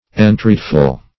Entreatful \En*treat"ful\, a. Full of entreaty.